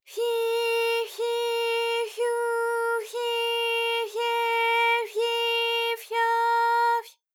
ALYS-DB-001-JPN - First Japanese UTAU vocal library of ALYS.
fyi_fyi_fyu_fyi_fye_fyi_fyo_fy.wav